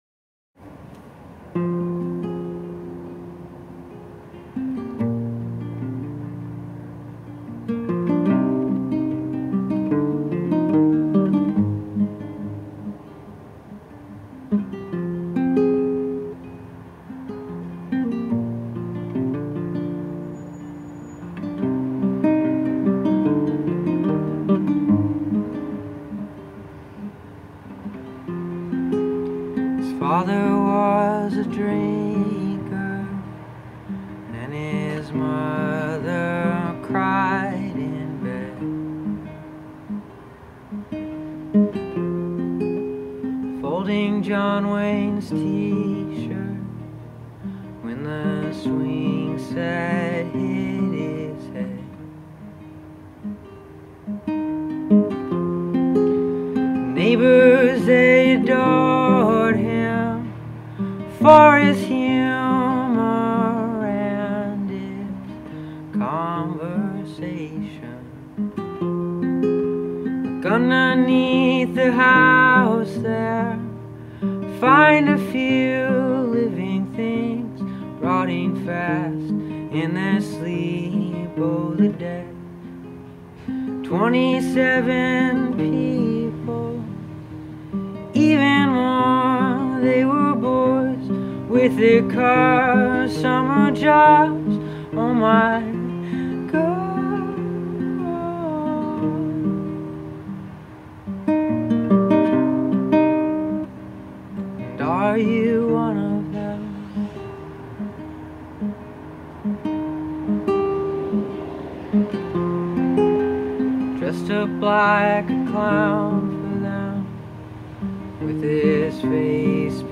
There is something so piercing about his voice.